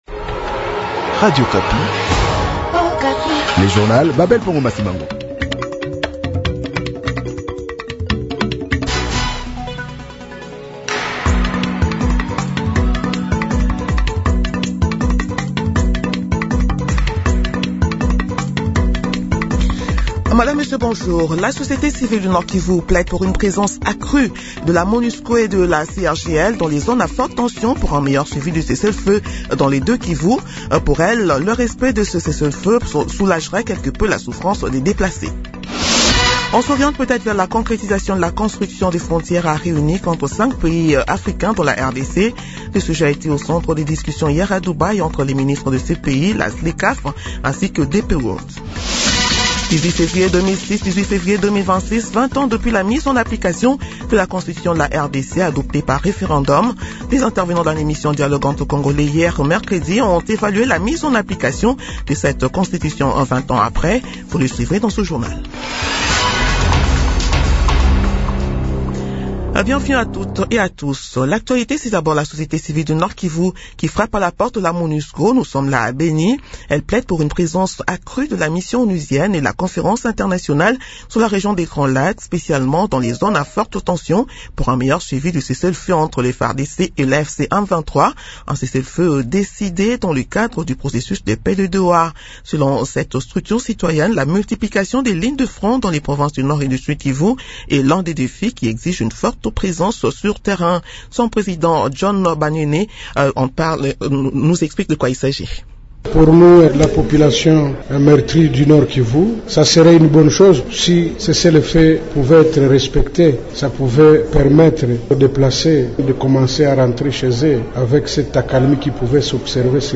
Journal matin 8 heures